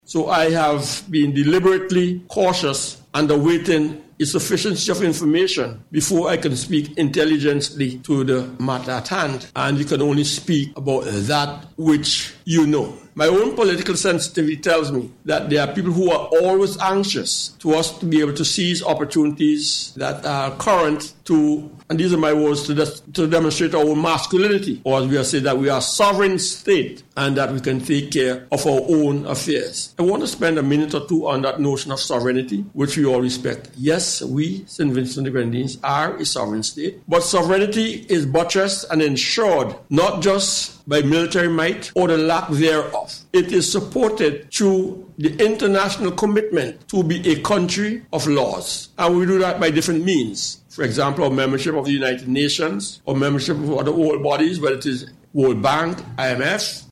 Minister of National Security, Major the Hon. St Claire Leacock addressed public concerns, noting the government’s deliberate caution in responding.